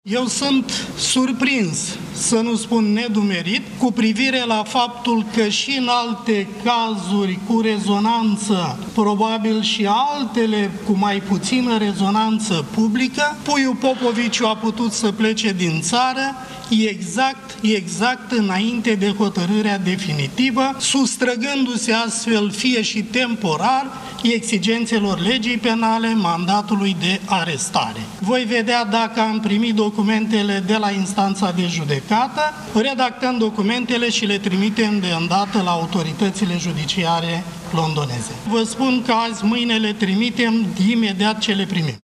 Tudorel Toader a declarat miercuri dimineață că instituția pe care o conduce lucrează la documentele de extrădare.